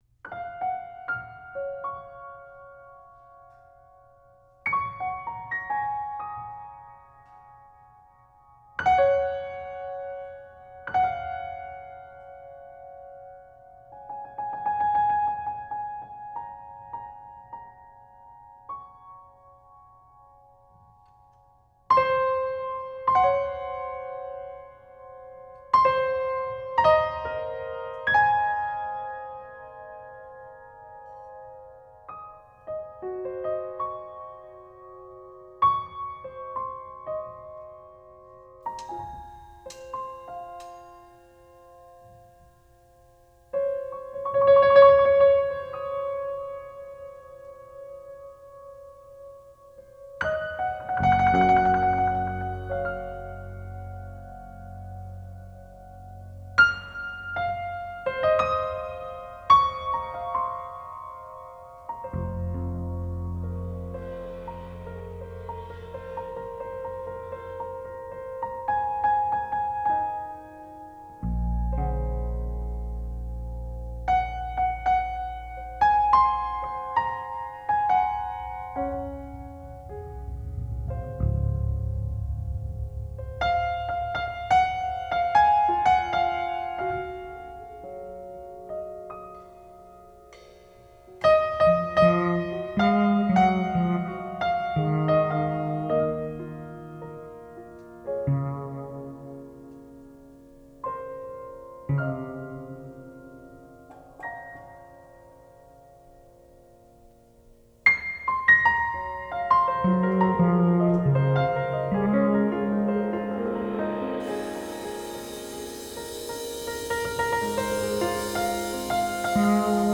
piano
fretless electric bass
drums